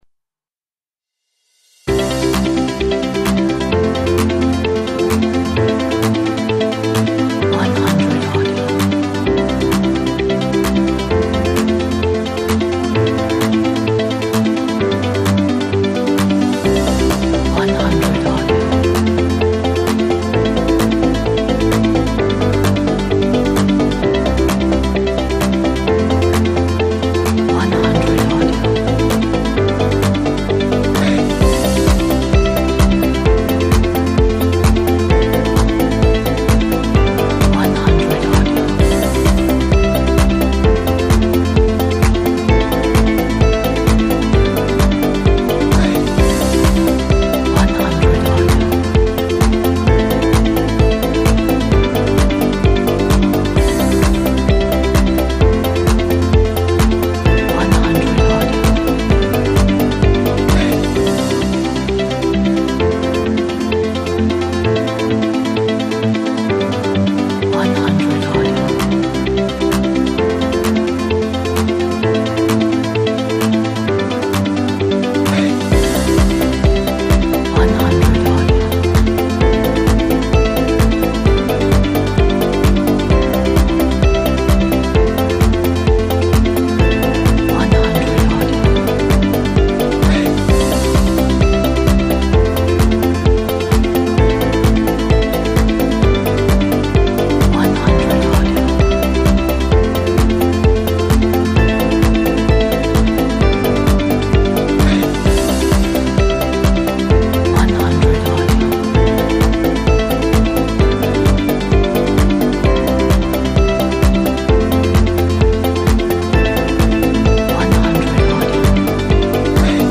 Very inspiring!